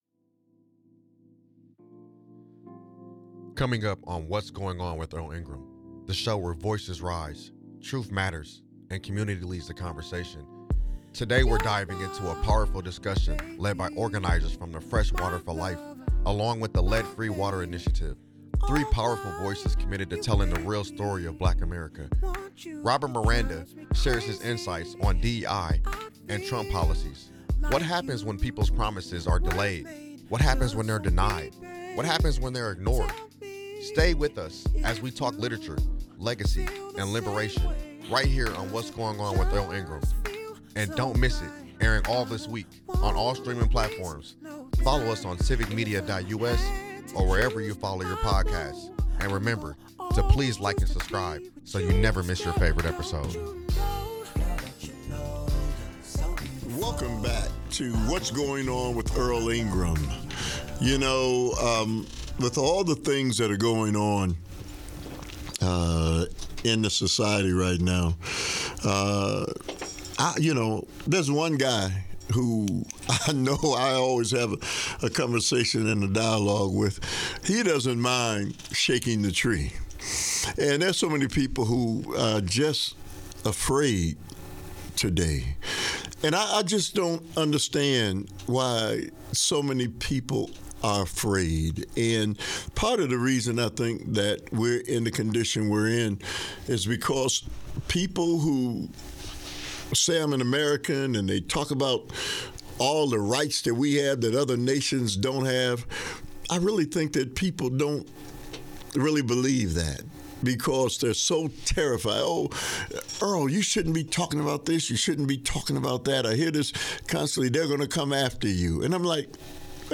a powerful conversation you don’t want to miss. We’re digging deep into the harsh realities of racism in America , the dangerous implications of Trump’s influence and Project 2025 , and how the rise of the ultra-wealthy is fueling the collapse of the middle class .